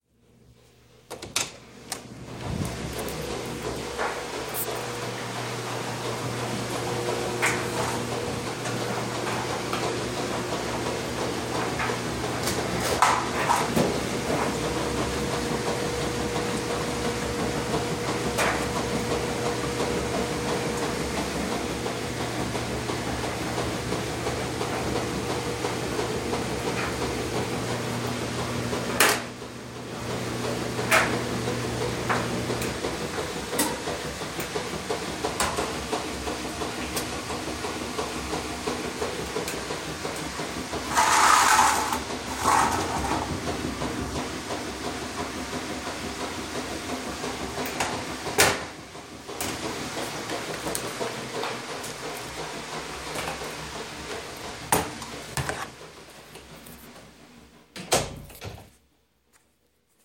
Laundry Room I was standing in the laundry room, where both washers and dryers were going. There is a faint humming in the background and the most prominent noise is the clicking of most likely something metal inside the dryer. Though the sound is not as clear in this recording as it was in person, I hoped to capture the rhythmic feel that I got standing inside the laundry room.